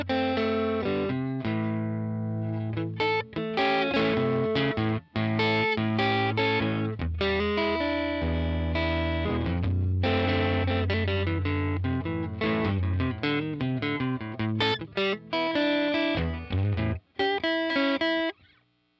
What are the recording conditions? Recorded using SM57 and Tone Tubby 212 bomb.